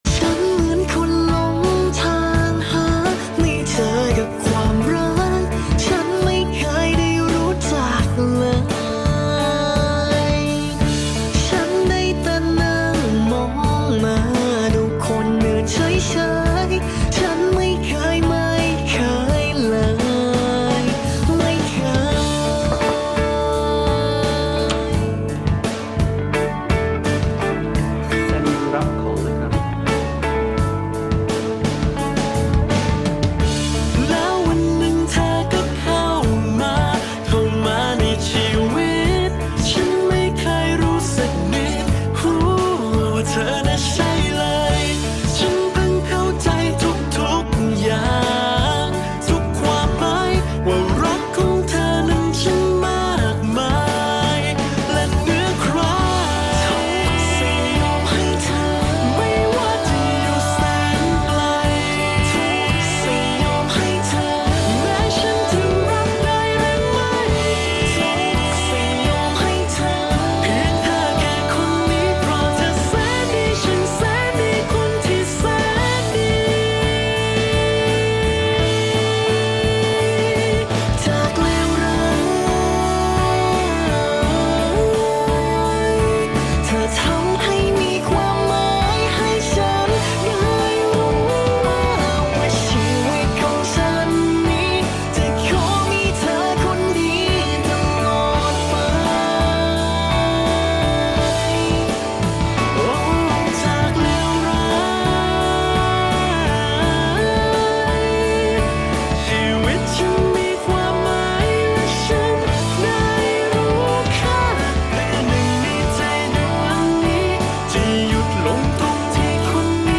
ซีนนี้ดีมากๆ “อย่าหนีไปไหนอีกนะ” มันไม่ใช่ประโยคคำสั่งเเต่เป็นประโยคขอร้องที่อบอุ่นมากๆ ใช้สายตาอ้อนวอนและน้ำเสียงแบบนุ่มๆอะ ตอนถามอันดามองอะไร